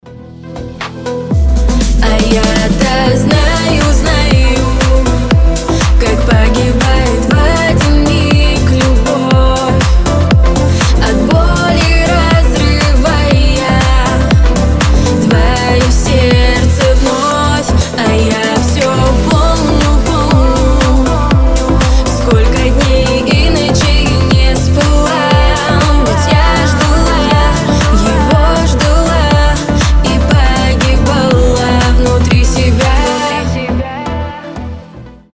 • Качество: 320, Stereo
грустные